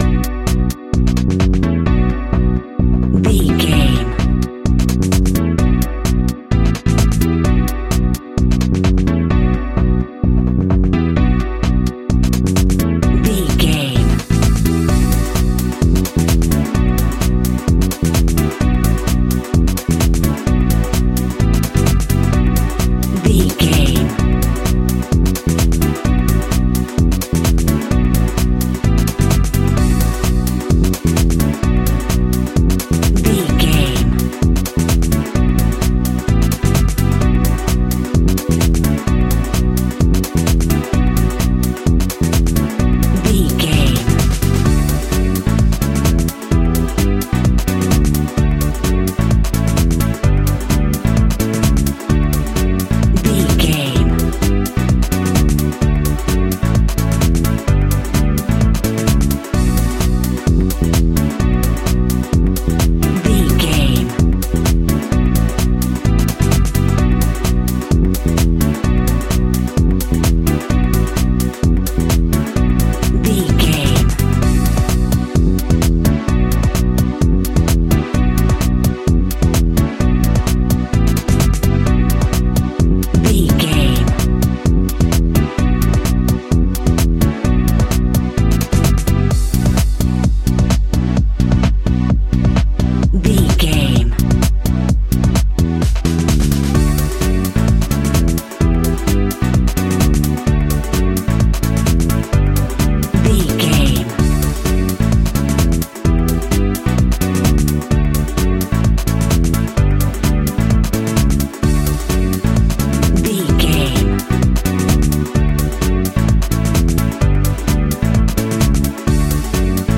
Aeolian/Minor
groovy
uplifting
driving
energetic
bass guitar
drums
strings
piano
electric piano
disco
nu disco
synth
upbeat
funky guitar
clavinet
funky bass
horns
saxophones